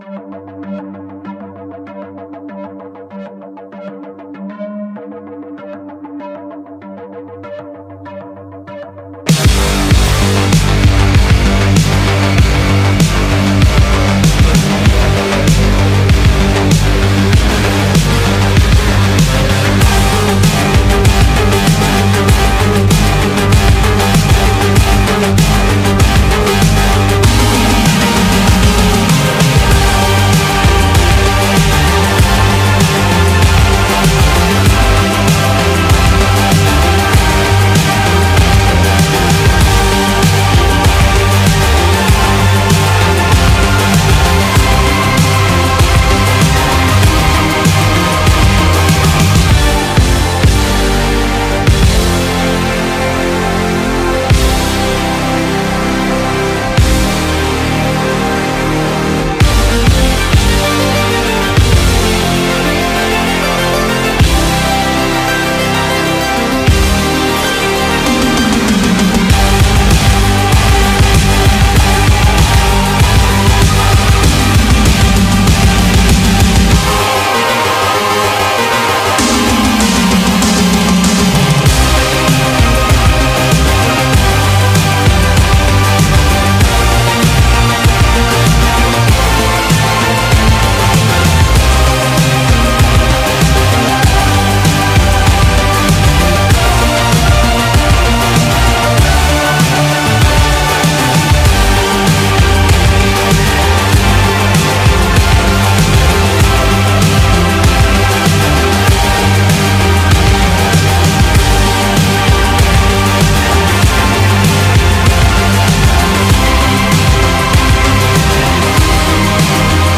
BPM97
Comments[DARK SYNTHWAVE]